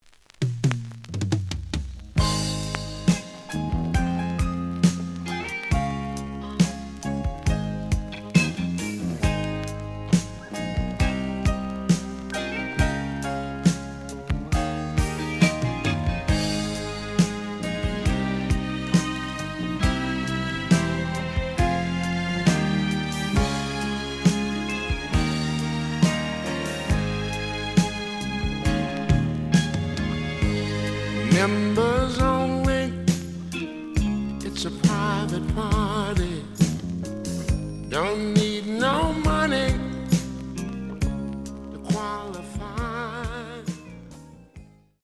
●Format: 7 inch
●Genre: Soul, 80's / 90's Soul